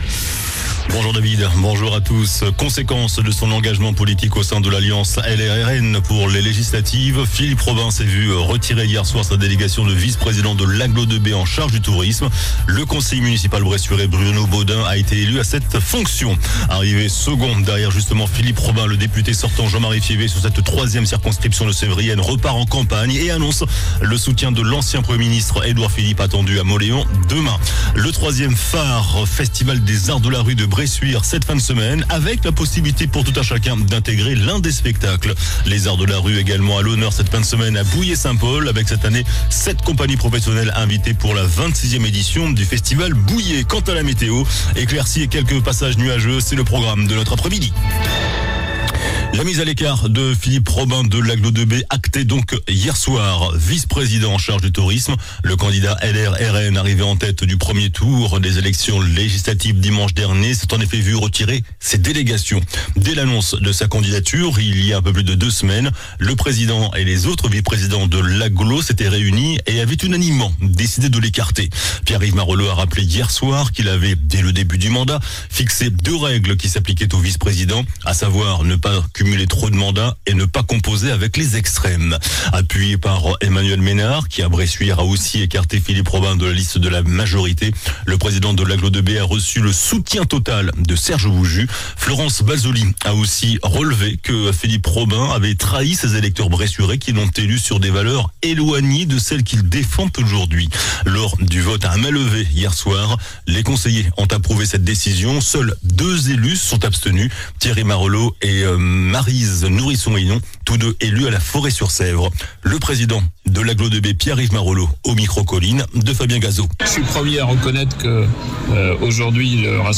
JOURNAL DU MERCREDI 03 JUILLET ( MIDI )